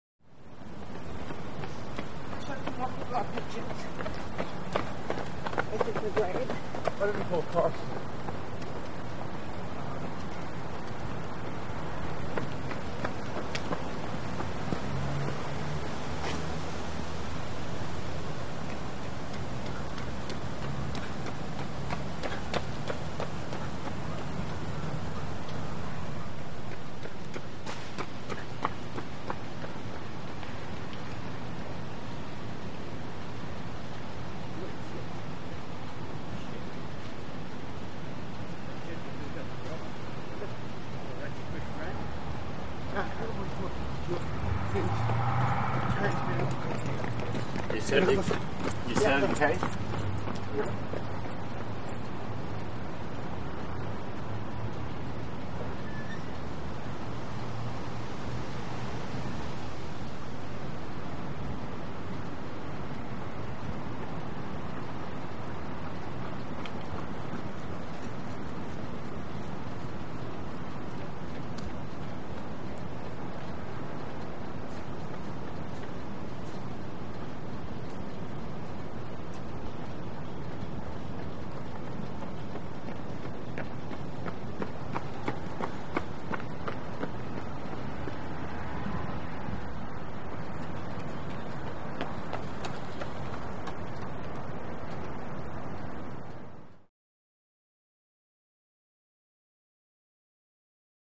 Joggers By On Asphalt Medium Close, Some Walla Key Jingle On Bys. Mostly Singles. Wind In Trees, Distant Traffic Roar, Truck Rev.